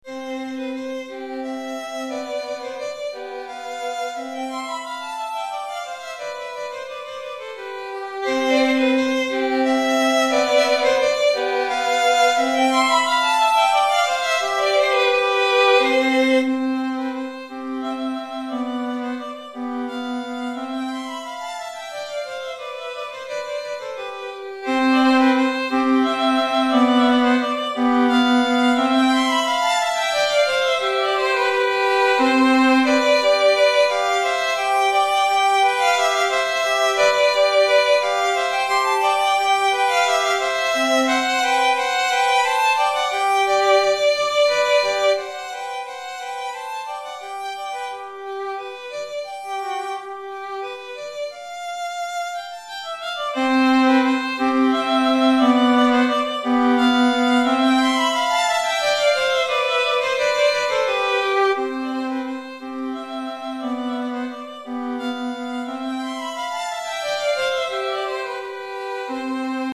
Répertoire pour Violon - 2 Violons